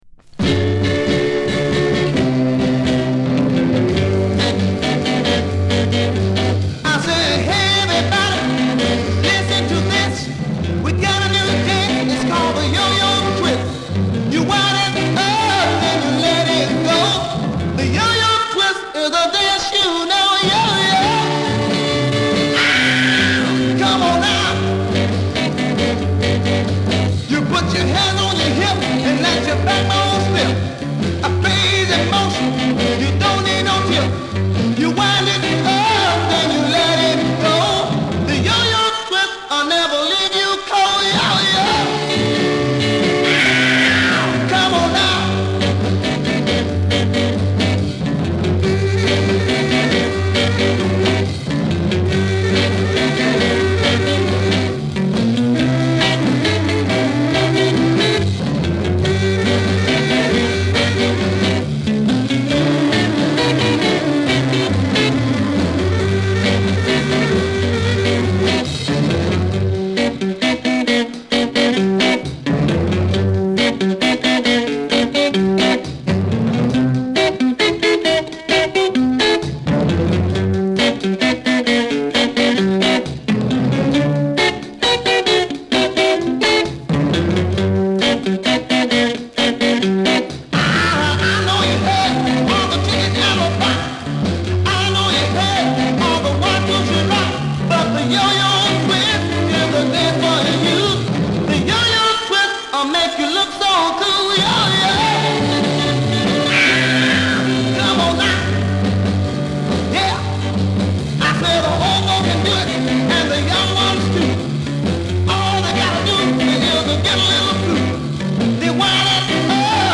• R&B / BLUES / DOO WOP / BLACK ROCKER